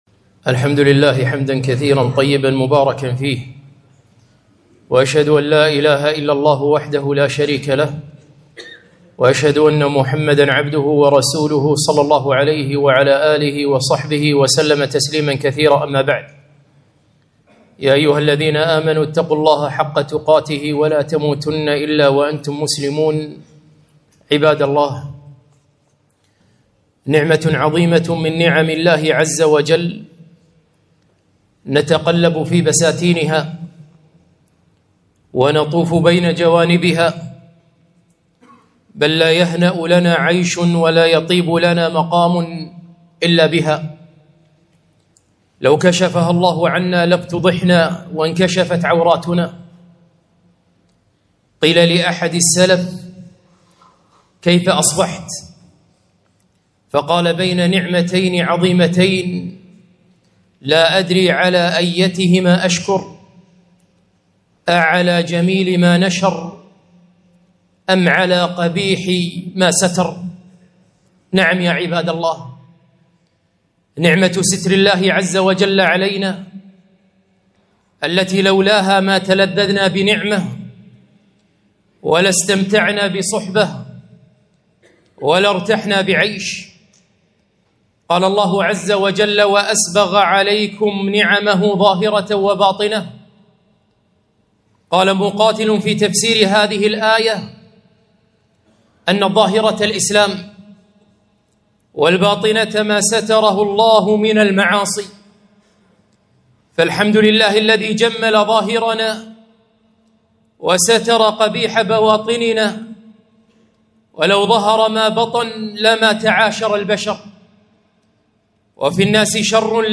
خطبة - ستر الله على عباده